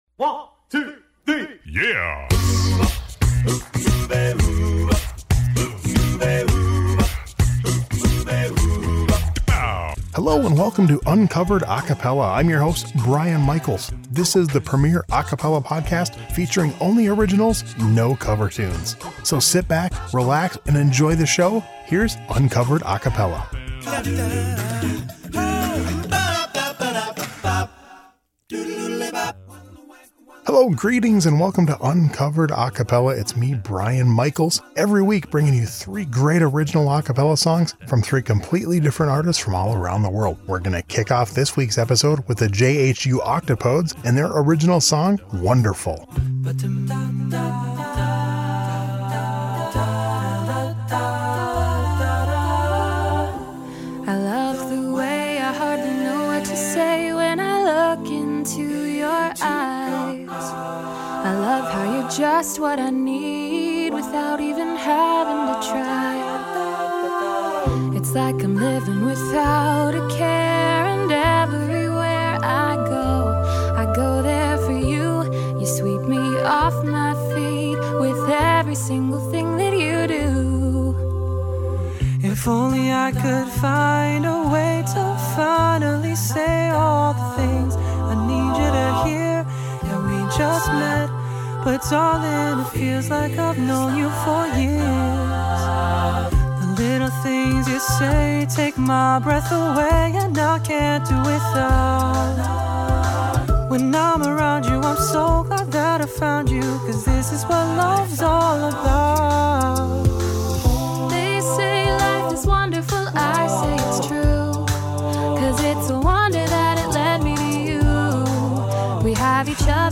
3 original a cappella songs every week!